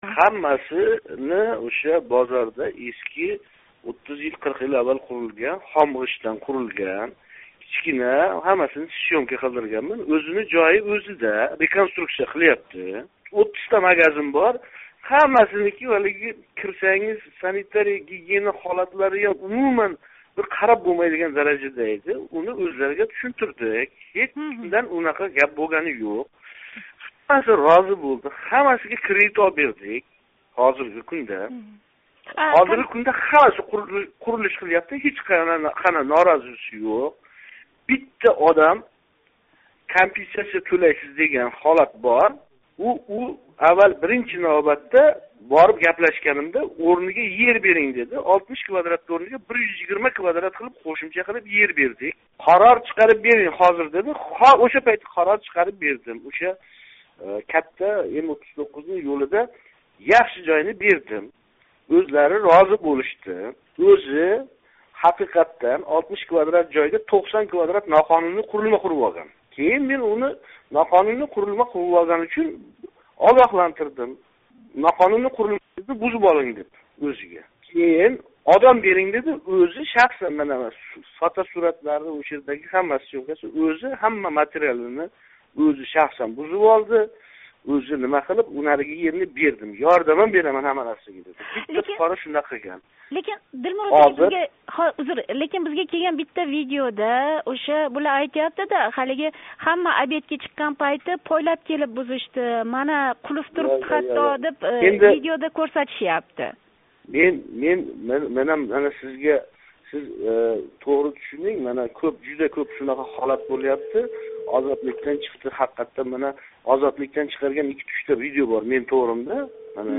Ҳоким Дилмурод Фозилов билан суҳбат